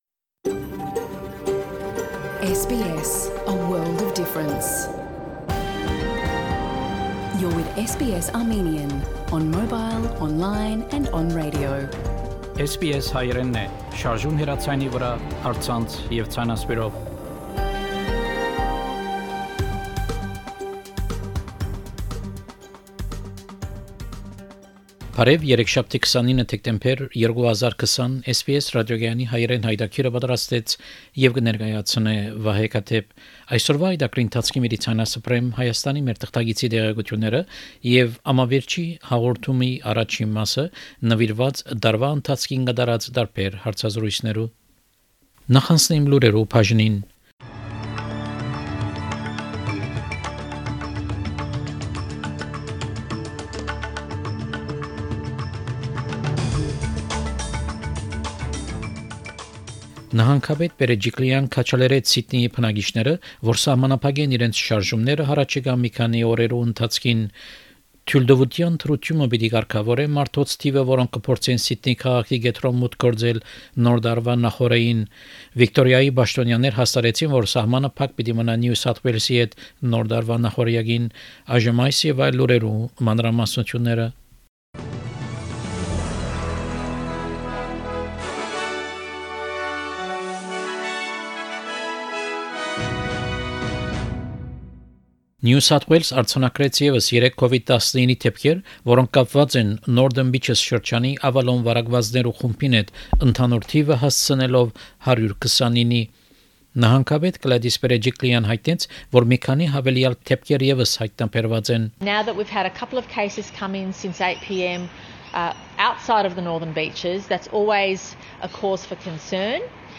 SBS Armenian news bulletin – 29 December 2020
SBS Armenian news bulletin from 29 December 2020 program.